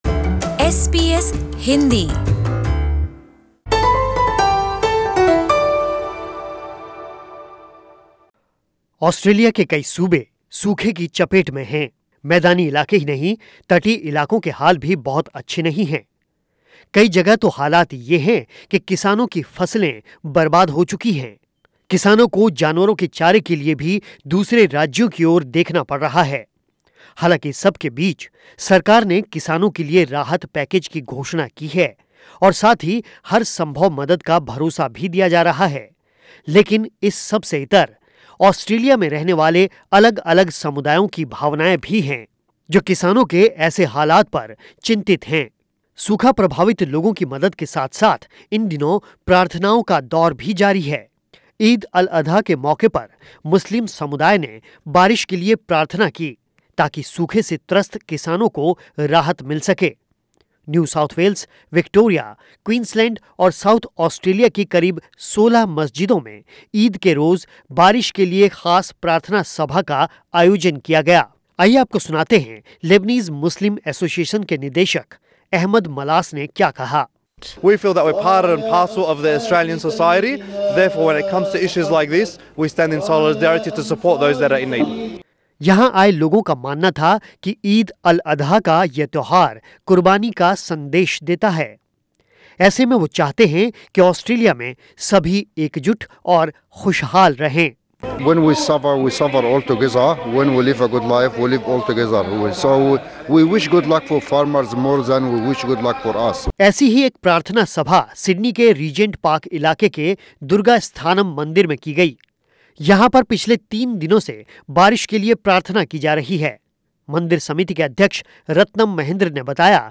और इन दिनों देश के अलग अलग इलाकों में बारिश के लिए प्रार्थनाओं का दौर जारी है. सुनिए इसी पर ये रिपोर्ट